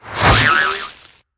you_win_pillow_appear_01.ogg